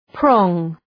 Προφορά
{prɔ:ŋ}